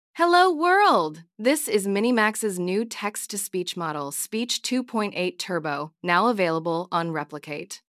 multilingual text-to-speech
Minimax Speech 2.8 Turbo: Turn text into natural, expressive speech with voice cloning, emotion control, and support for 40+ languages
"channel": "mono",
"voice_id": "Wise_Woman",